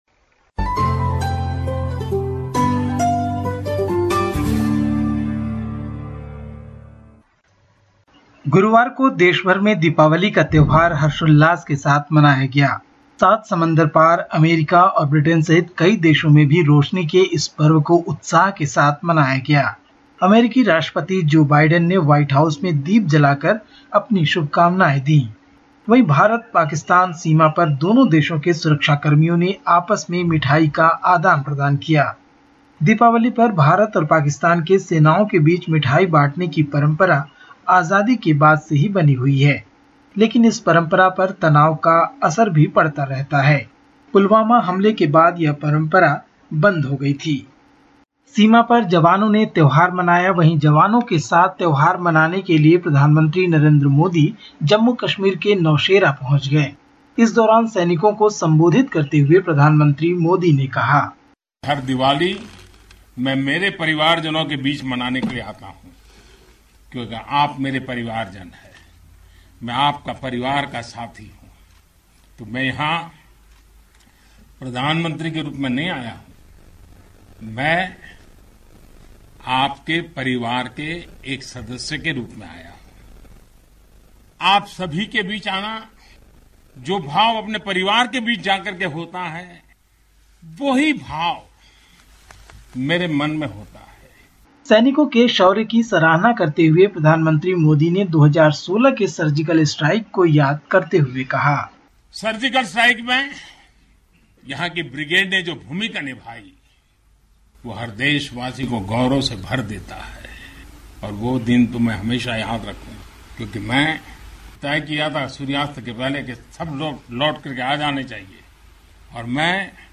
India report in Hindi: Indian Prime Minister celebrates Diwali with country's army troops